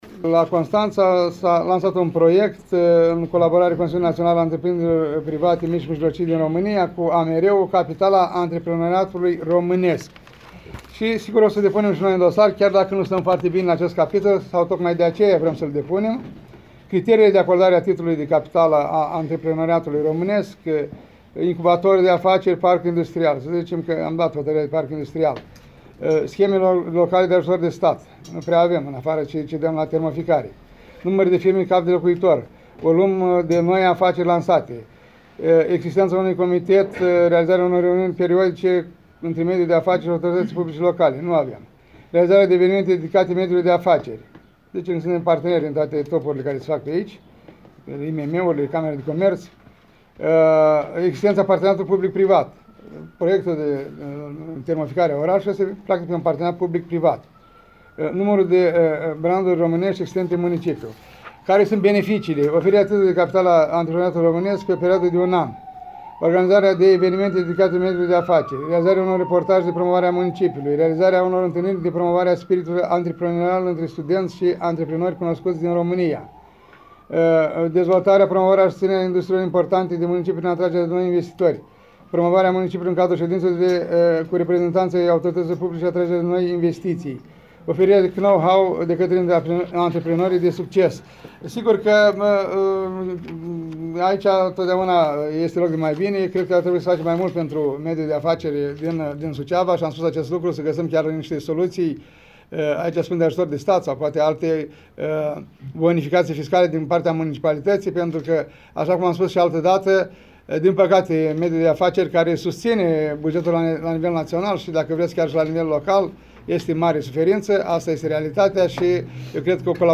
Ion Lungu, primarul municipiului Suceava: